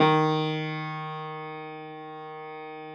53h-pno07-D1.aif